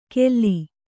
♪ kelli